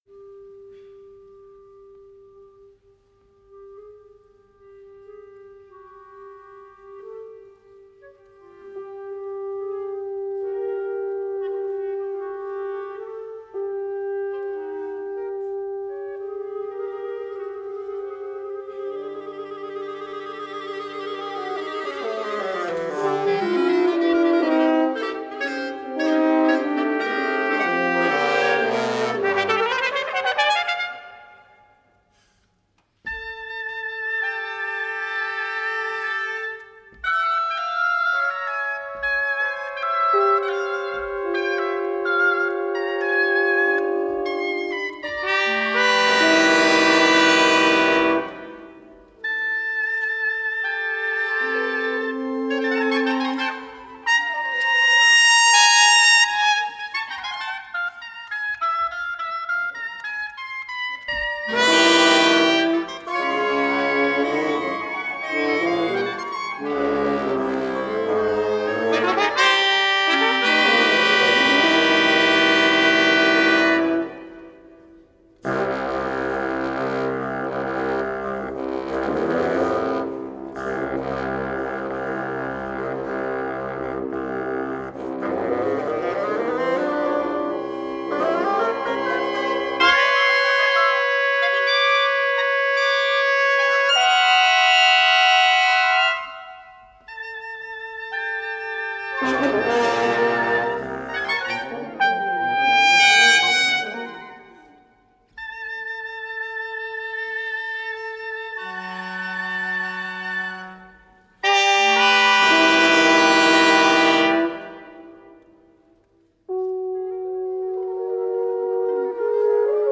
per ensemble di fiati / for wind ensemble (2015)